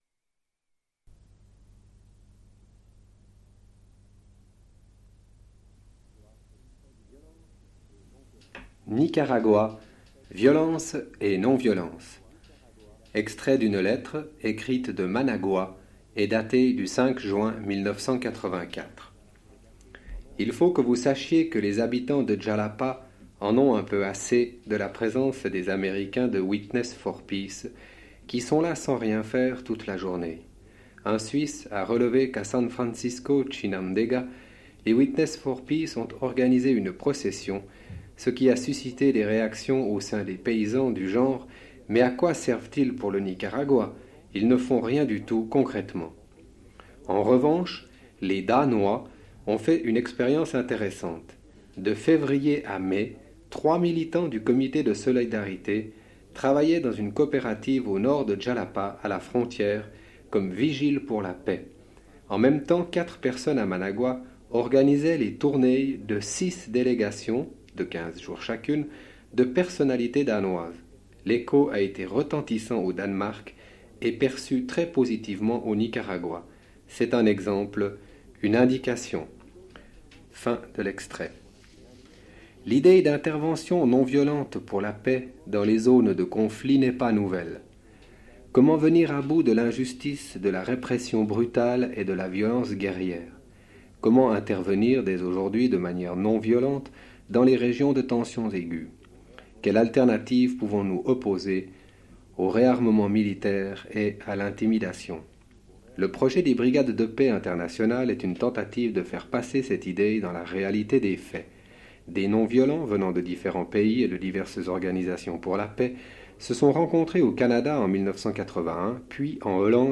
Lecture d'extrait d'une lettre du 5 juin 1984, écrite à Malagua.